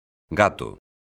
gato_son.mp3